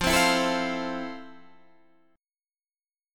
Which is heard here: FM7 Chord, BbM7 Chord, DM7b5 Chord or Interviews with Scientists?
FM7 Chord